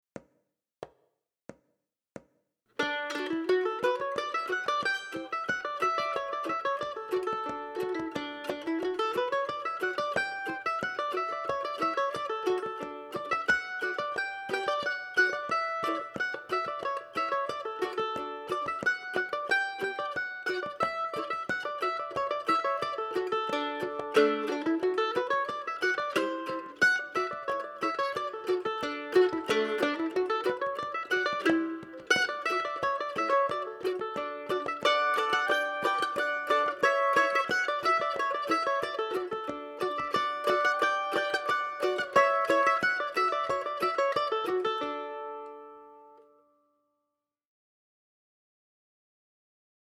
DIGITAL SHEET MUSIC - MANDOLIN SOLO
Celtic/Irish
Play-Along Online Audio